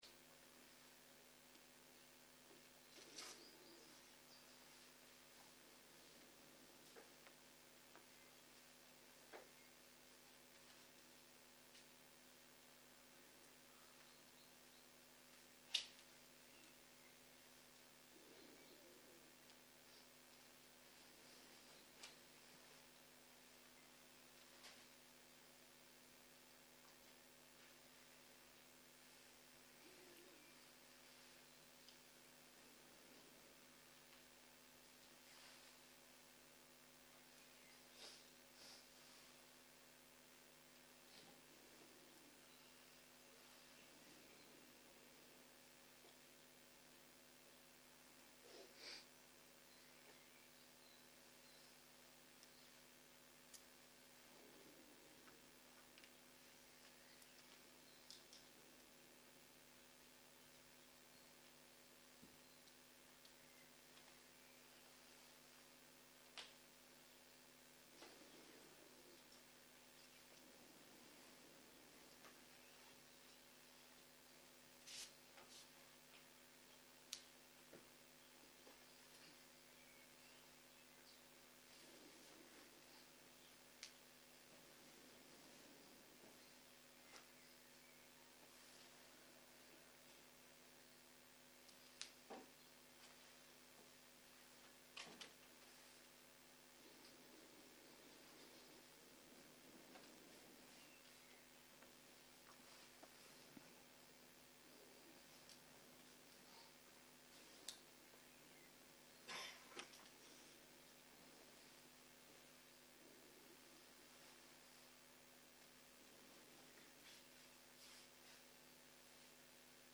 07.03.2023 - יום 5 - בוקר - מדיטציה מונחית - הקלטה 8
Guided meditation שפת ההקלטה